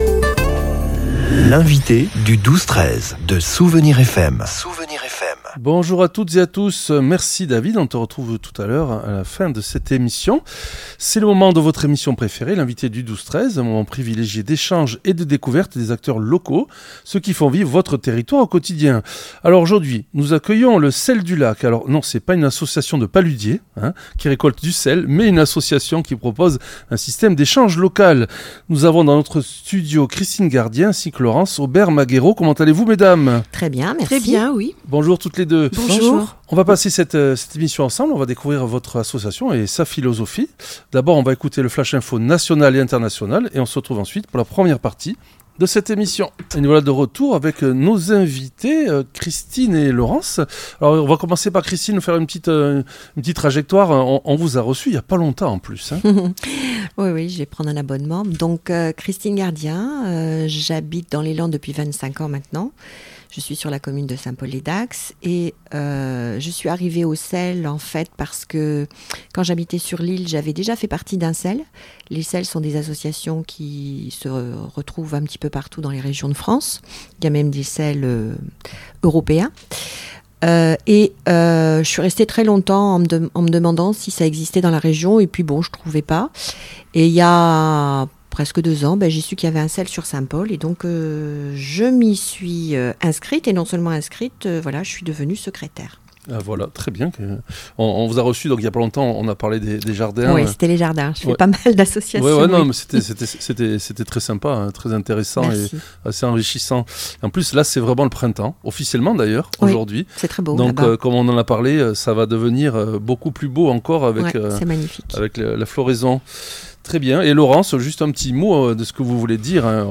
L'entretien a permis de lever le voile sur le fonctionnement de cette monnaie virtuelle, garantie sans spéculation ni taux d'intérêt.